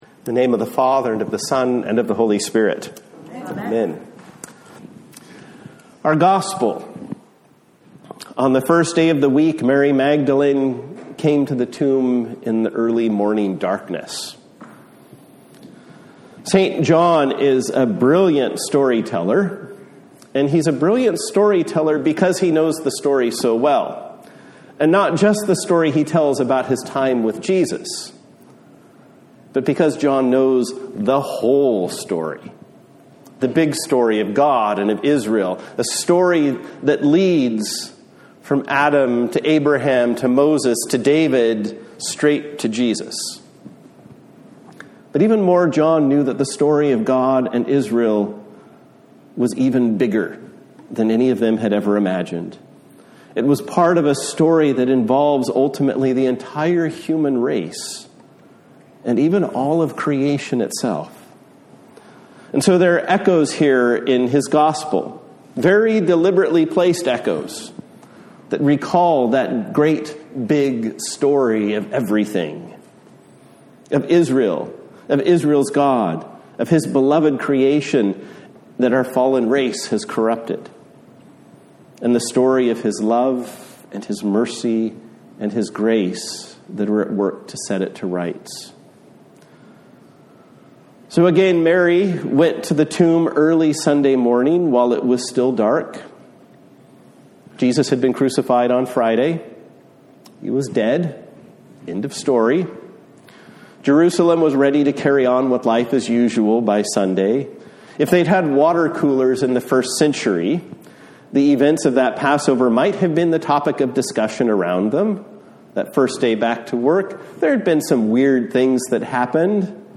A Sermon for Easter Day